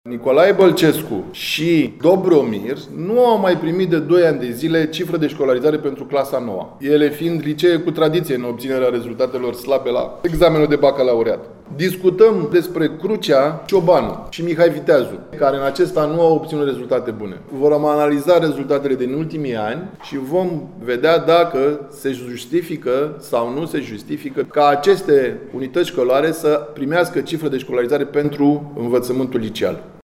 Explică şeful Inspectoratului Şcolar Judeţean Constanţa, Răducu Popescu: